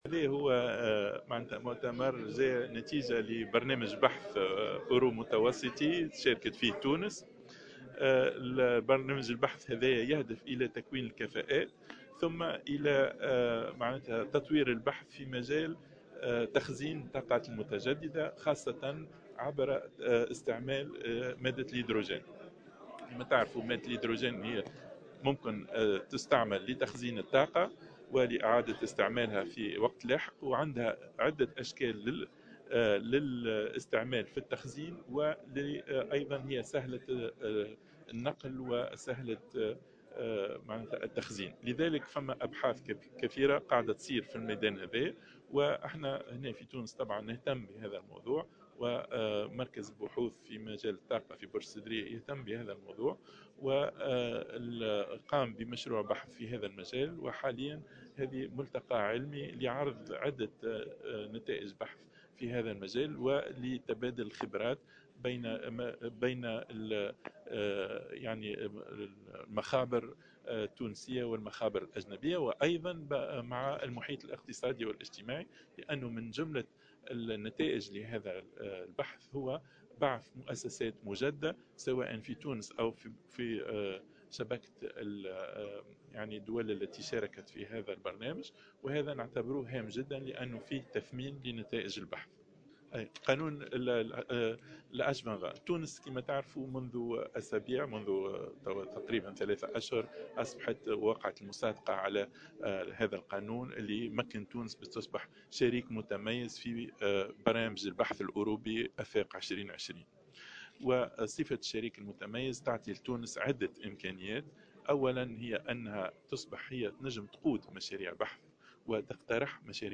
وقال في تصريحات صحفية على هامش مؤتمر دولي حول الطاقة الخضراء بمركز بحوث وتكنولوجيات الطاقة في تونس إن انعقاد هذا المؤتمر كان نتيجة بحث أورو متوسطي، شاركت فيه تونس ويهدف إلى تكوين كفاءات تبحث في آليات تخزين الطاقات المتجددة عبر استعمال الهيدروجين.